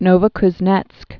(nōvə-kz-nĕtsk, nə-və-kz-nyĕtsk)